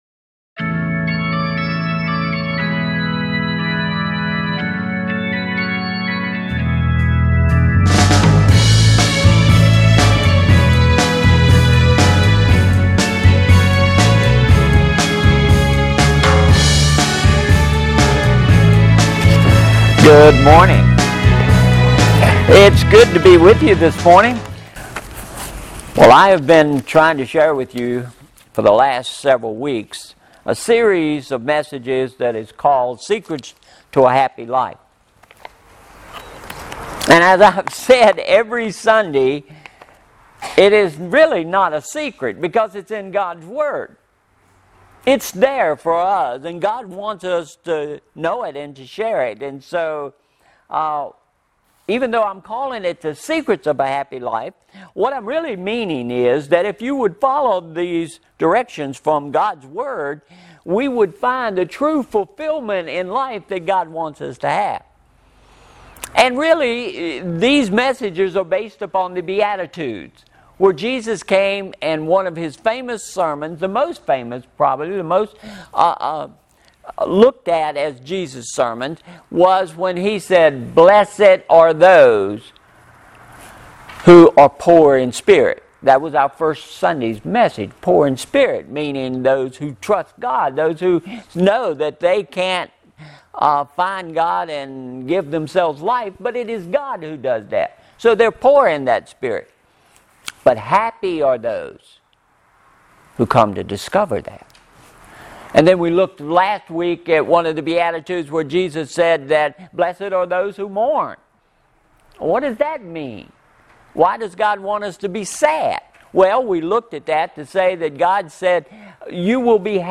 Why You Need To Stay Hungry | Boones Mill Church of the Brethren